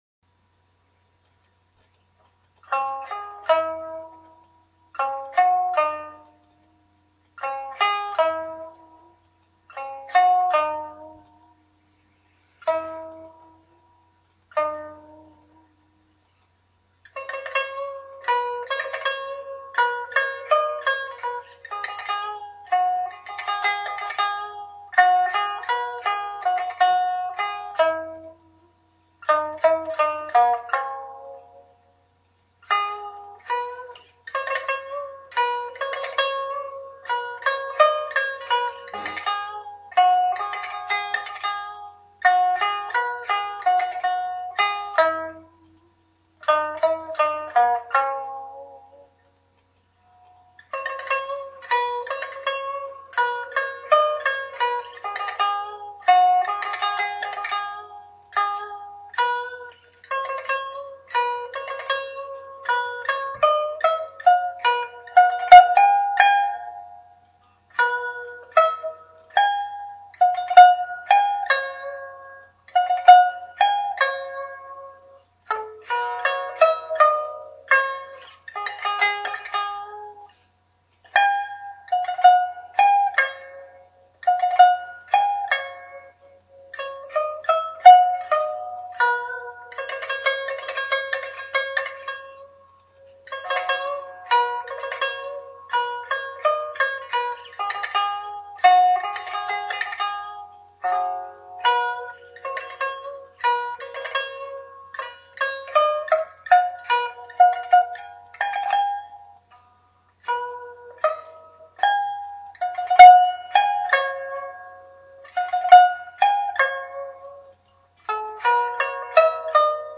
on Chinese Lute pipa